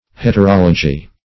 Heterology - definition of Heterology - synonyms, pronunciation, spelling from Free Dictionary
Heterology \Het`er*ol"o*gy\, n. [Hetero- + -logy.]